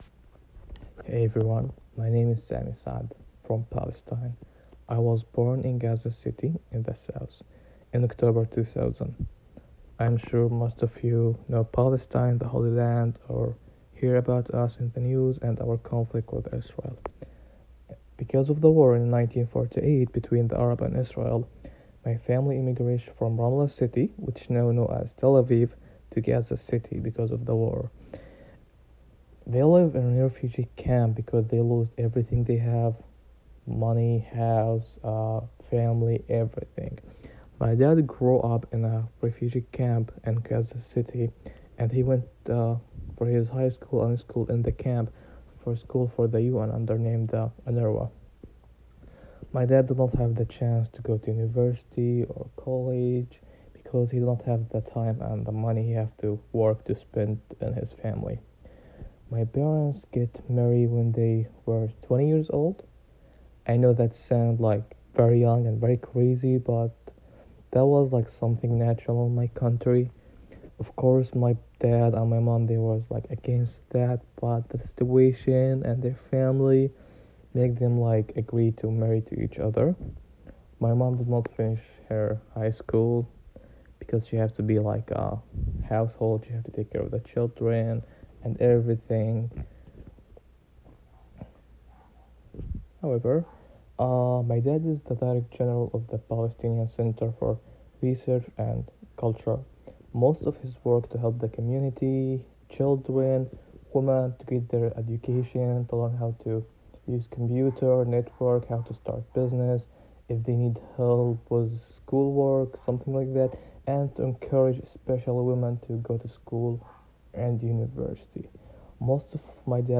An oral history archive of identity and experience across the globe.